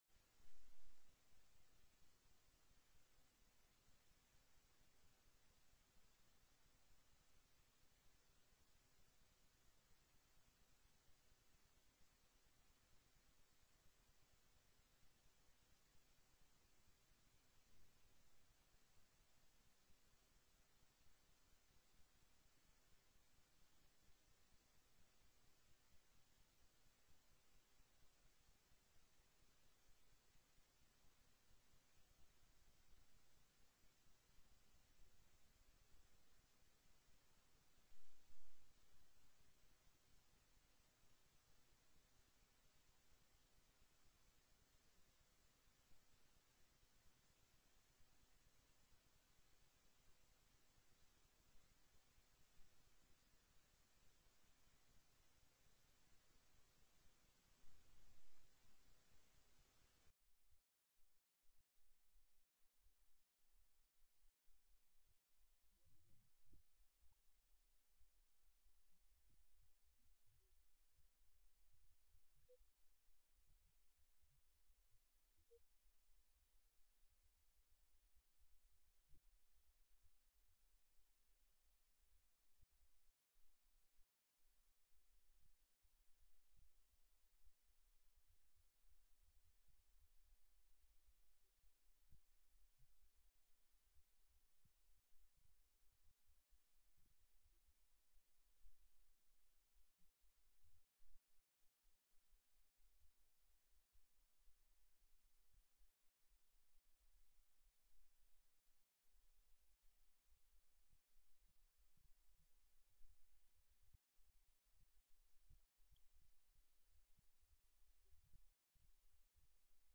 05/12/2007 09:00 AM House FINANCE